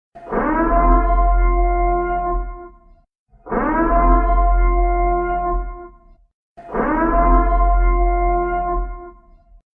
the purge siren.mp3
the-purge-siren.mp3